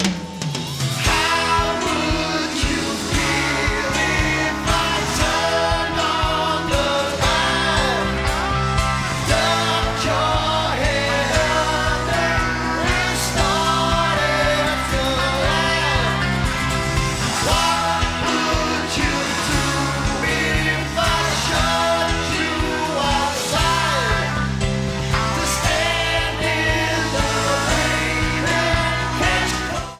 CD Comments: No noticeable sound differences. .